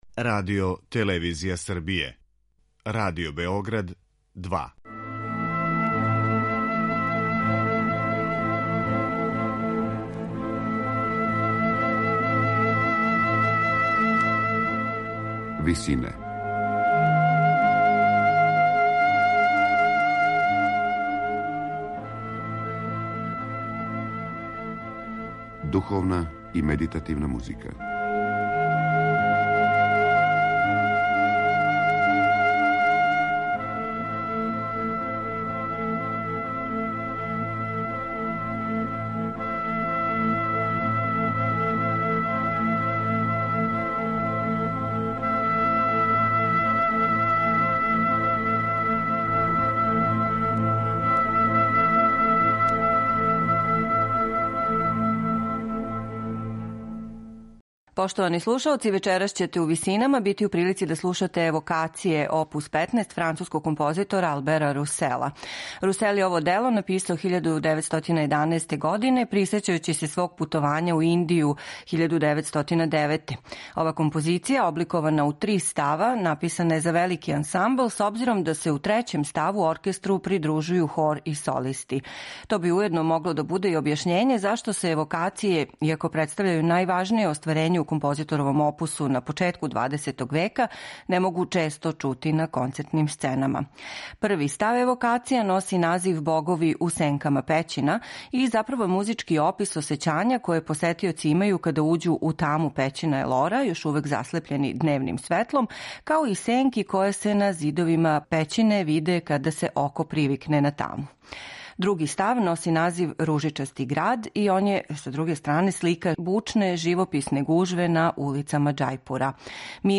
Вокална симфонија